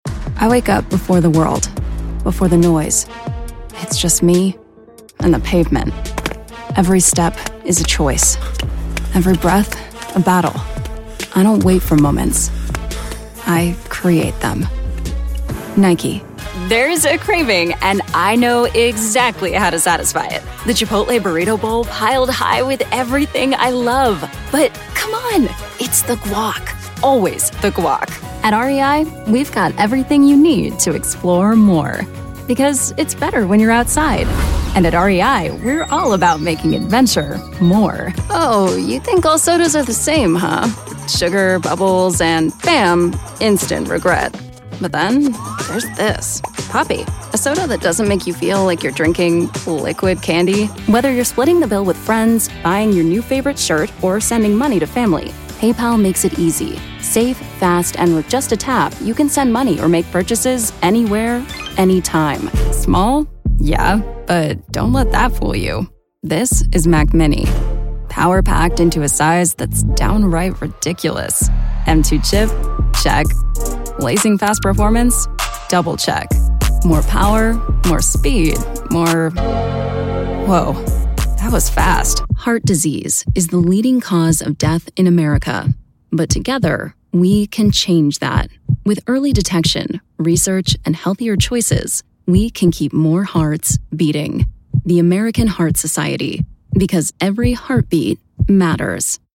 Chaleureux
Gravité
Intelligent